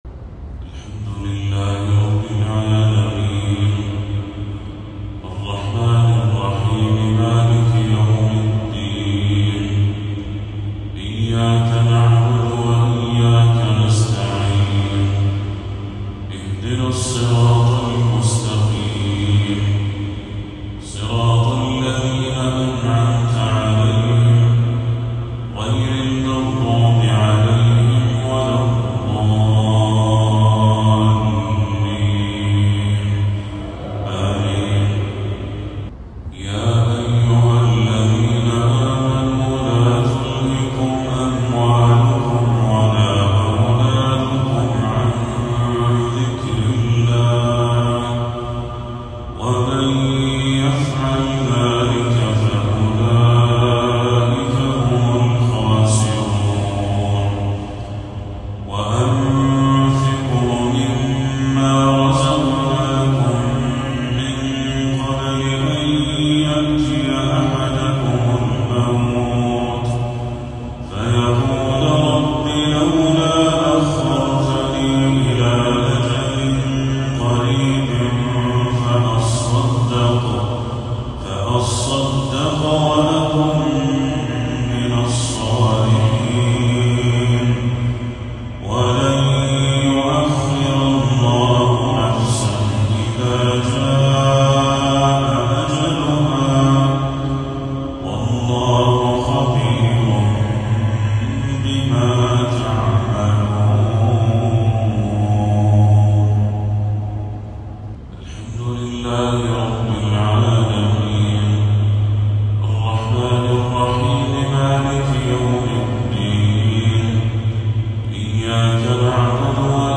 تلاوة بديعة لخواتيم سورتي المنافقون والمعارج